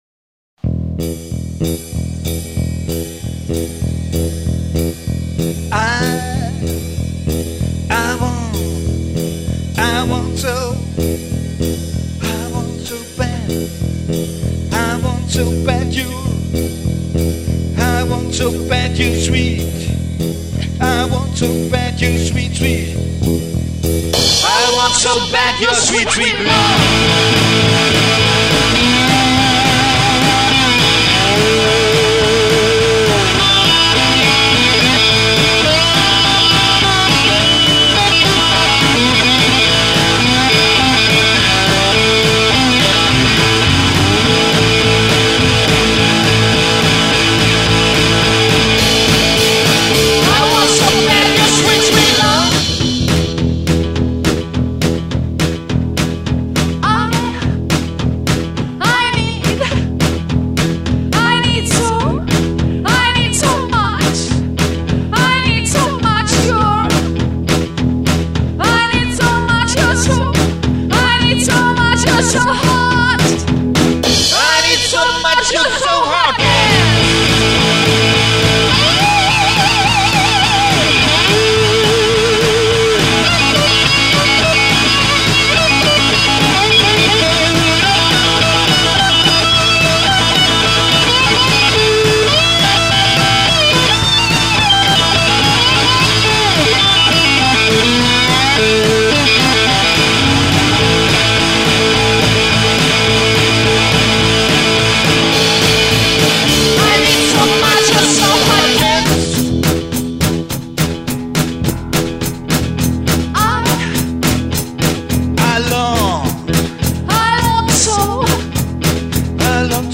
Mix aus Rock, Blues, Punk, Rap und Balladen
Drums, Cello,Vocals
Guitar, Slideguitar, Vocals